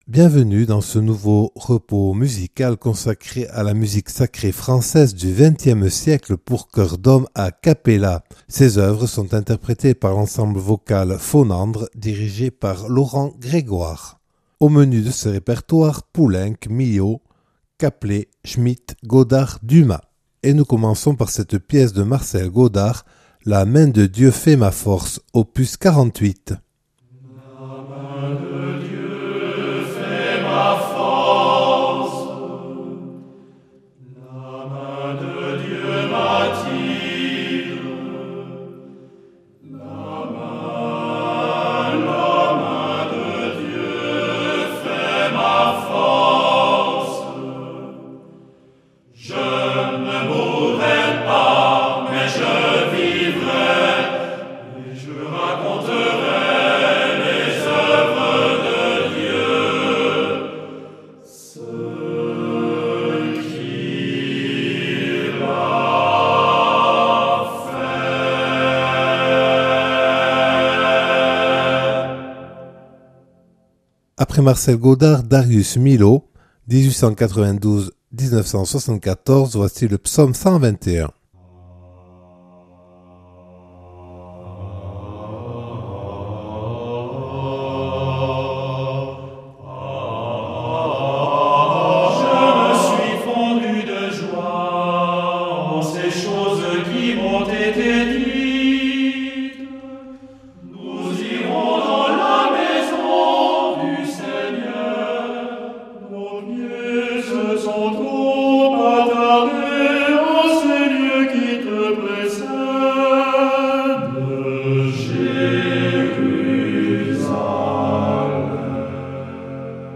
Musique Sacree XXe siecle